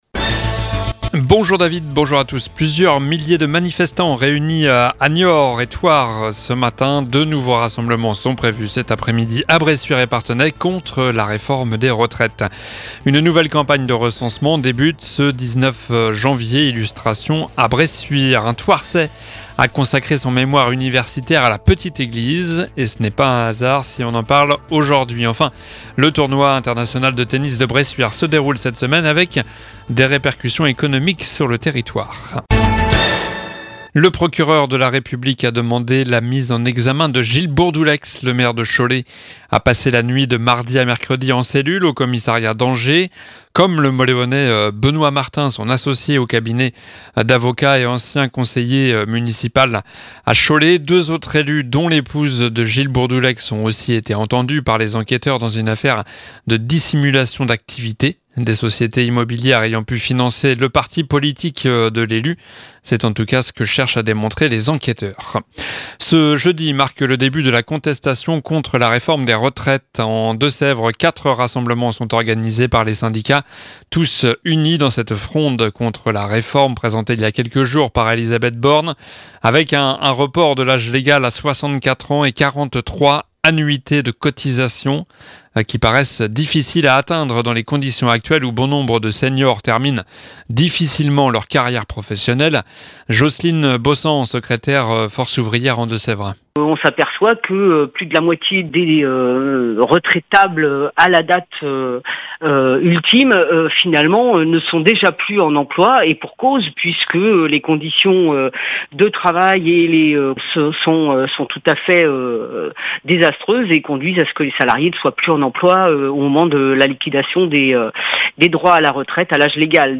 Journal du jeudi 19 janvier (midi)